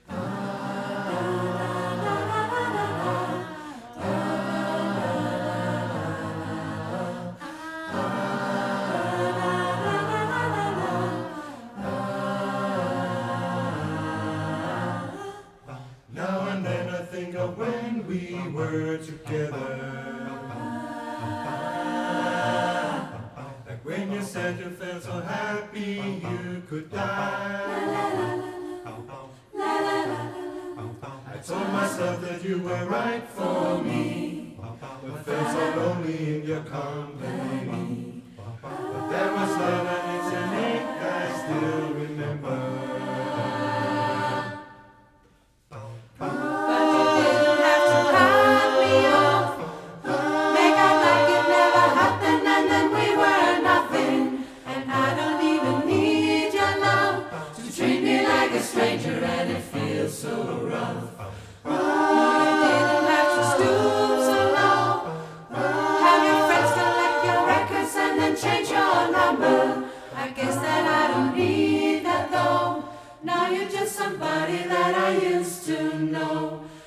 Et rytmisk kor i København